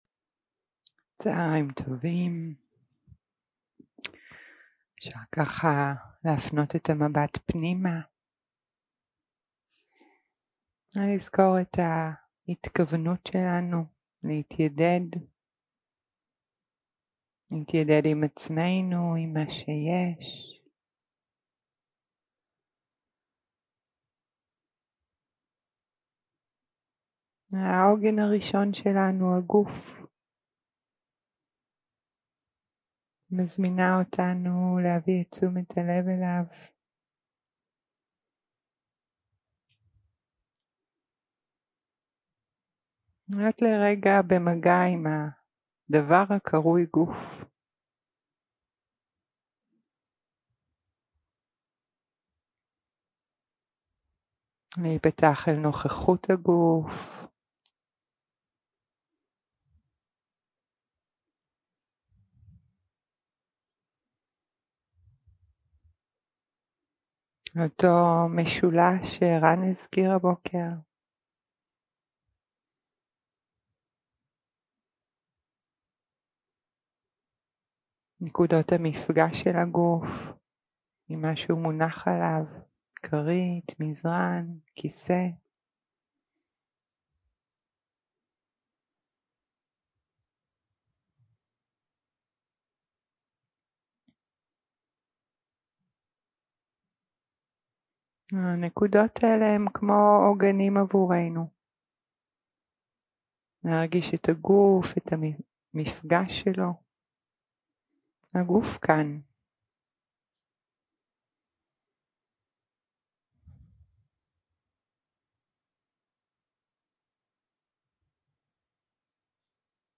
יום 2 – הקלטה 3 – צהריים – מדיטציה מונחית
Dharma type: Guided meditation